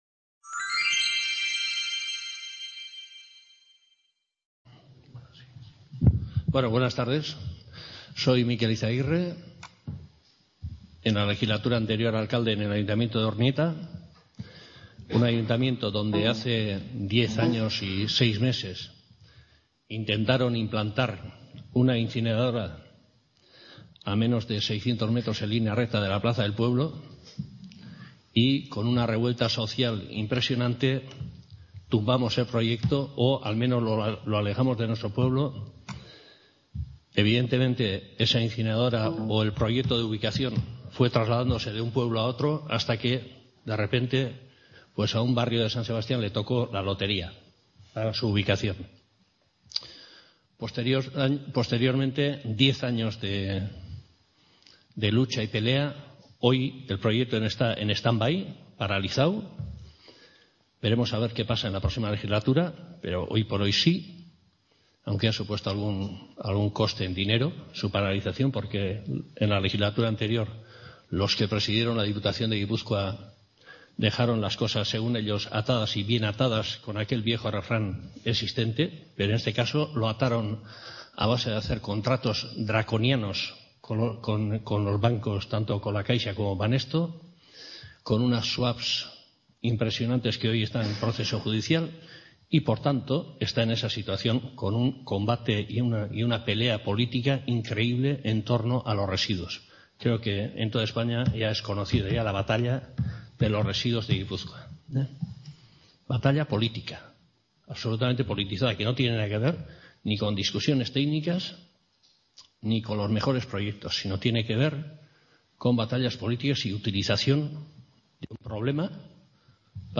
Ponencia
C.A. Ponferrada. II Jornadas Otra Economía es Posible: Gestión de residuos y creación de empleo.